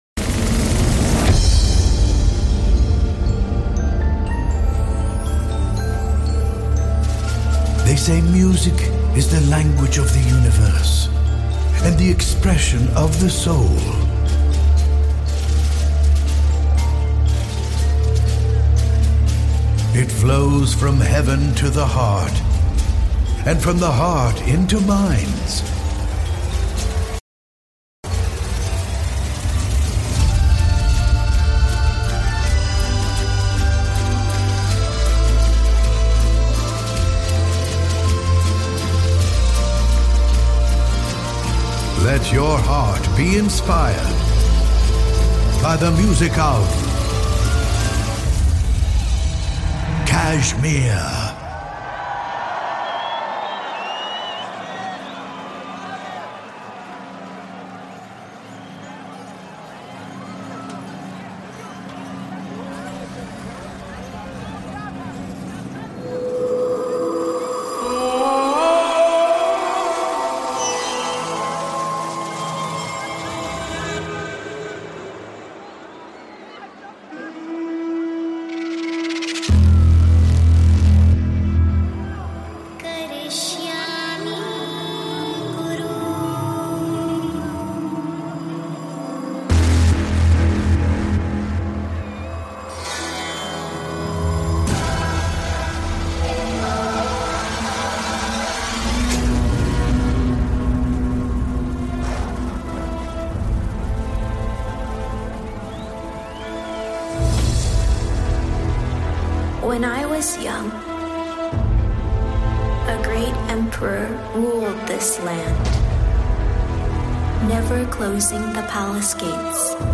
Also find other EDM Livesets, DJ Mixes
Liveset/DJ mix